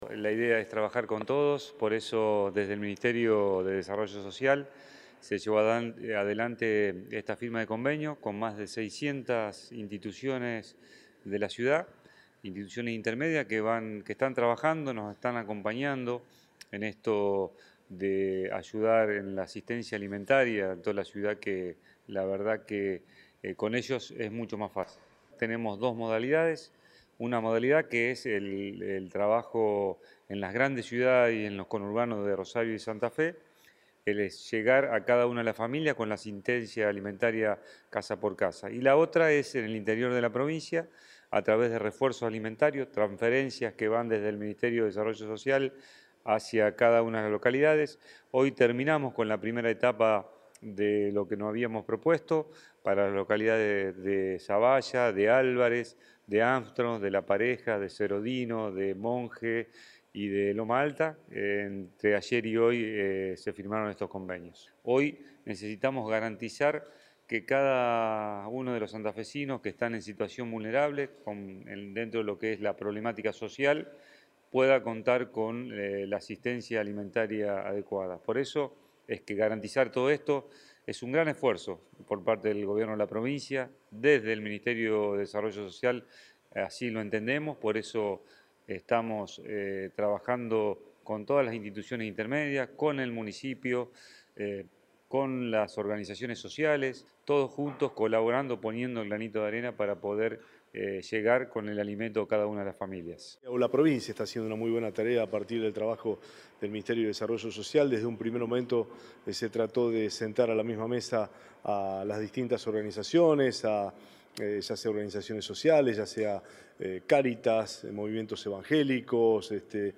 Palabras de Capitani